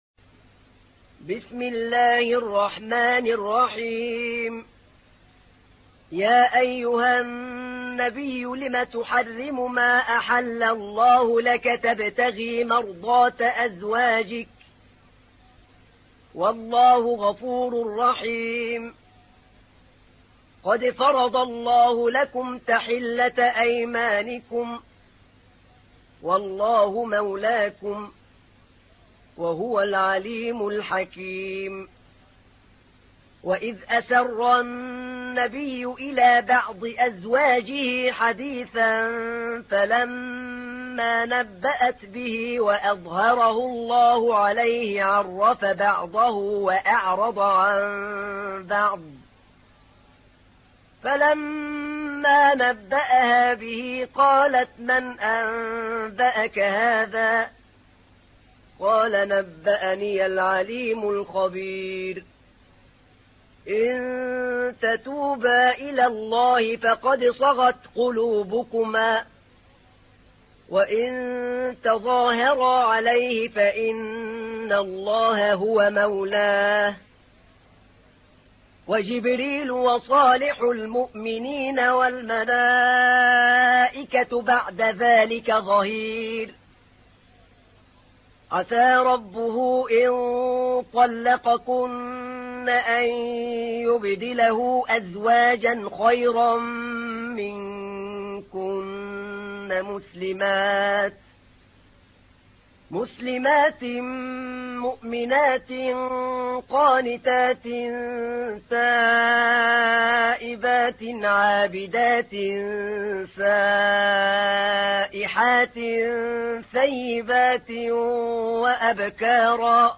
تحميل : 66. سورة التحريم / القارئ أحمد نعينع / القرآن الكريم / موقع يا حسين